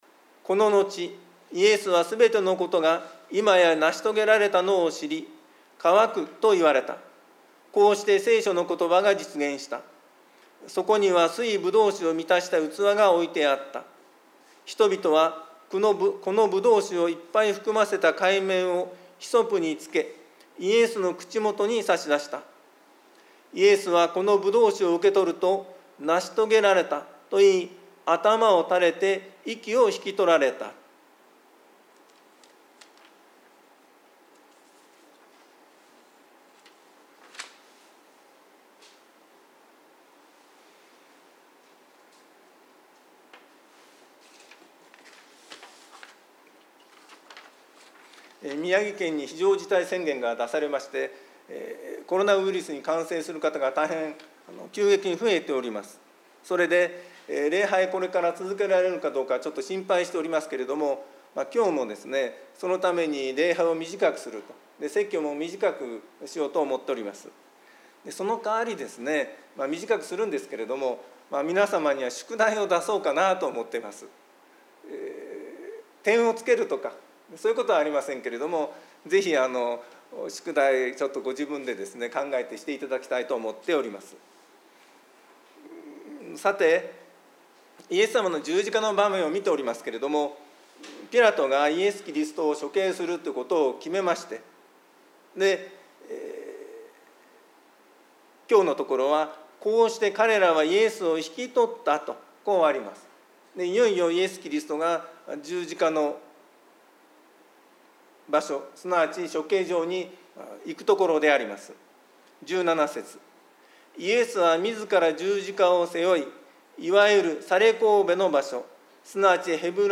毎週日曜日に行われ礼拝説教のアーカイブデータです。
礼拝説教を録音した音声ファイルを公開しています。